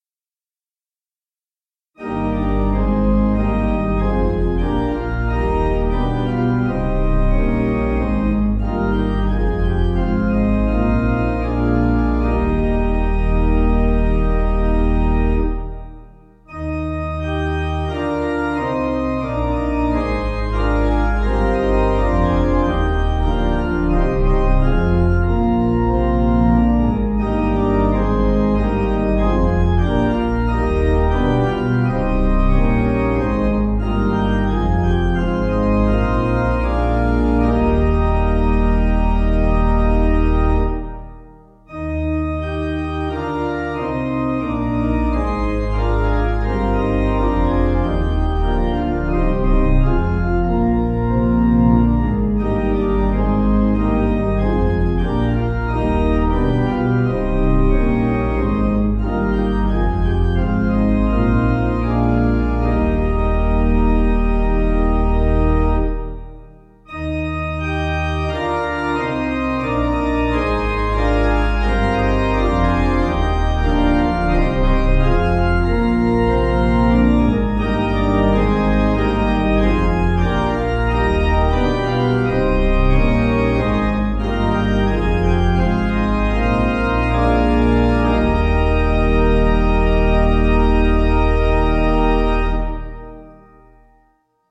Organ
(CM)   3/Eb